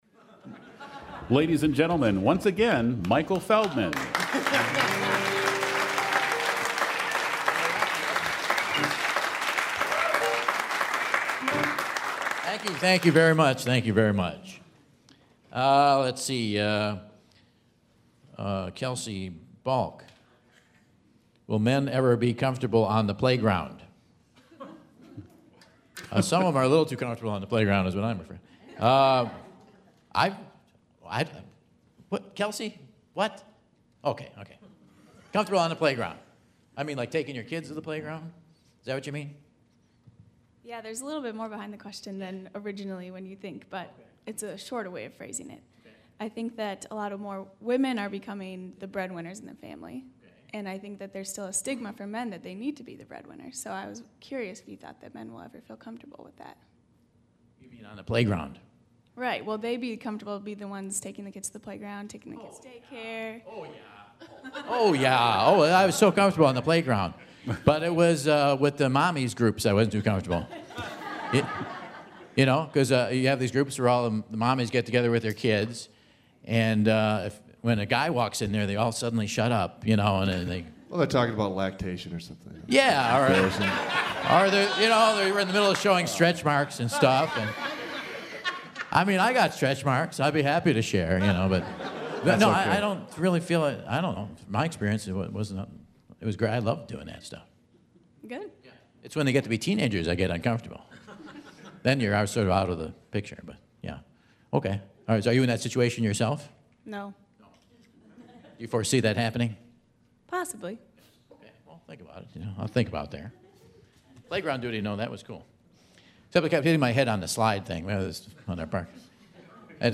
Two more fair ladies play the second round of the Whad'Ya Know? Quiz